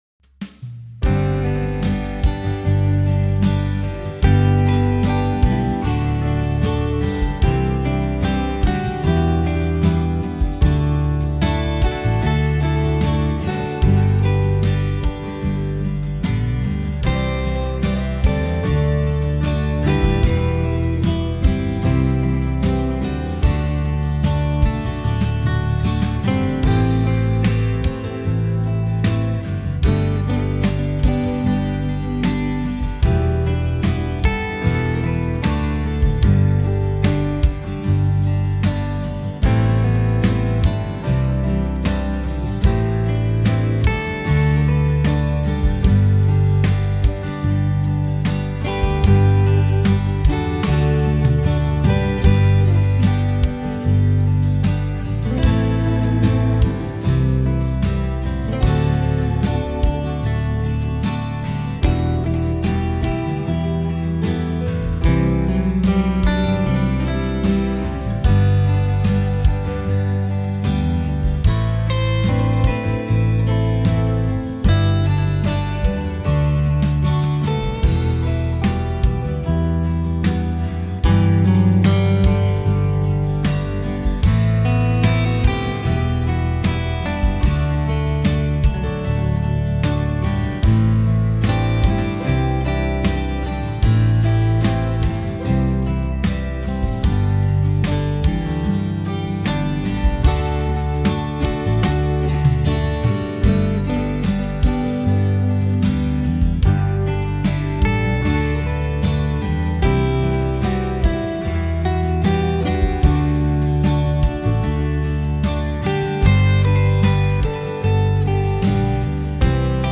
Home > Music > Blues > Smooth > Medium > Dreamy